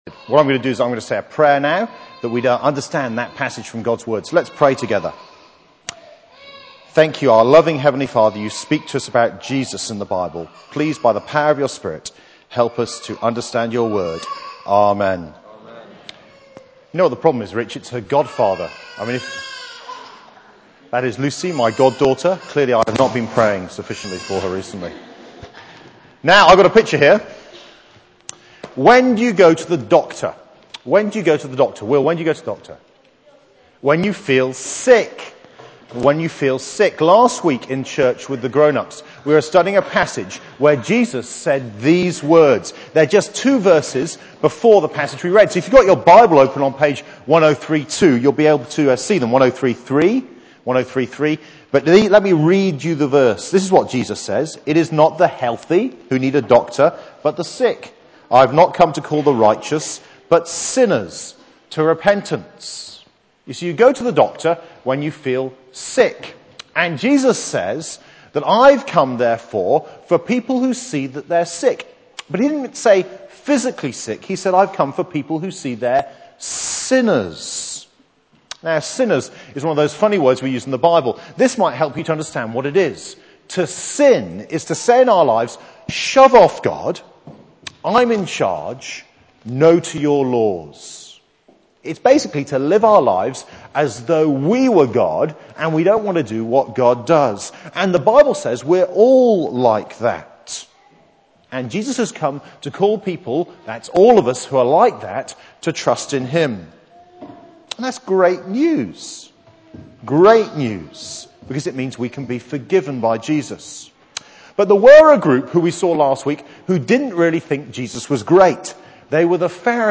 All Age Service